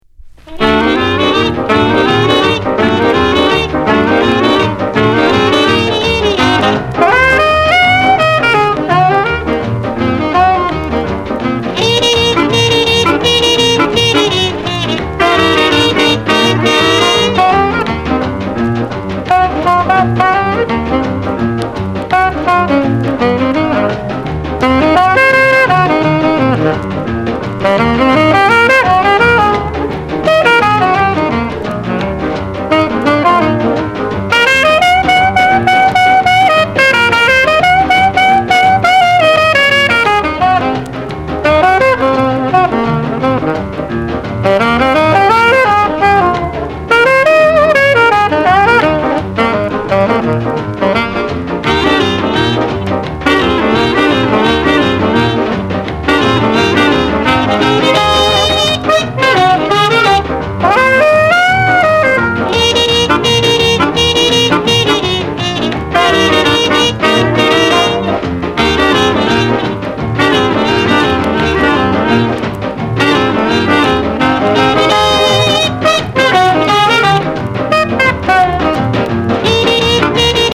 Genre: Rhythm & Blues